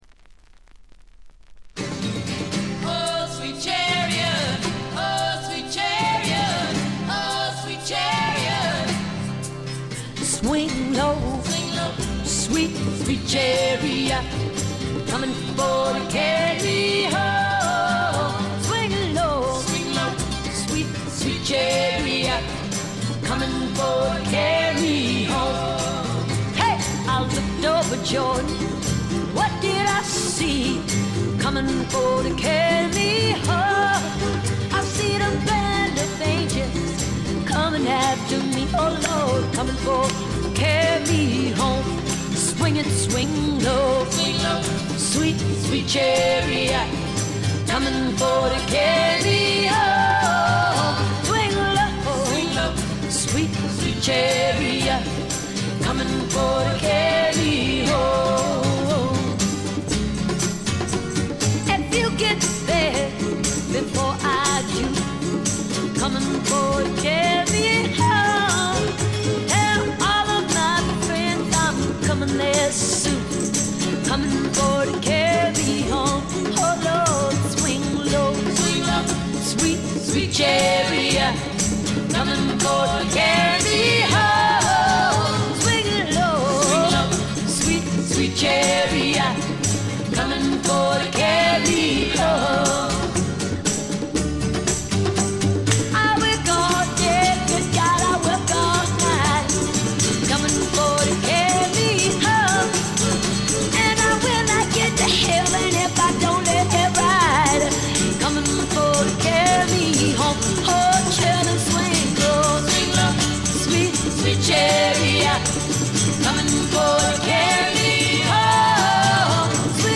全体に細かなチリプチ、バックグラウンドノイズが出ていますが気になるのはこのB1ぐらい。
美しいフォーク・アルバムです。
最初期のモノラル盤。
試聴曲は現品からの取り込み音源です。